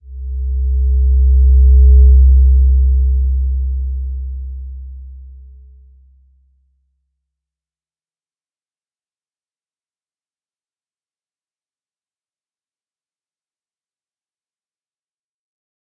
Slow-Distant-Chime-C2-p.wav